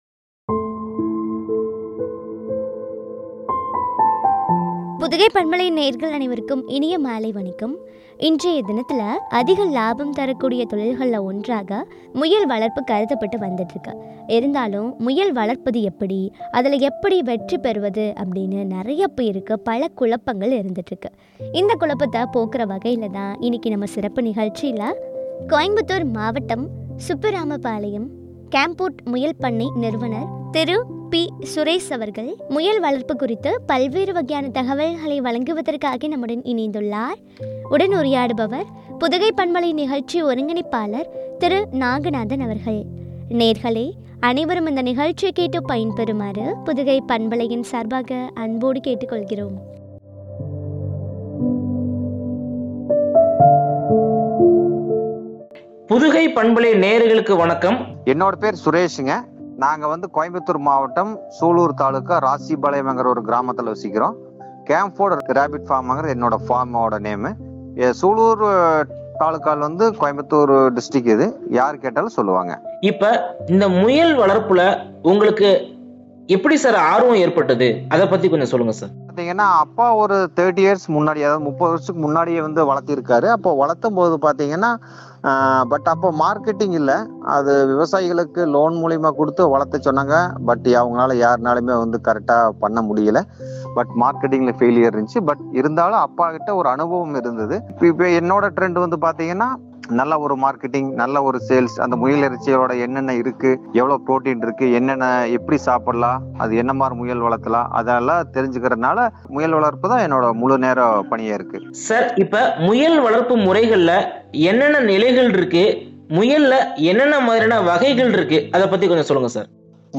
” லாபம் தரும் முயல் வளர்ப்பு” குறித்து வழங்கிய உரையாடல்.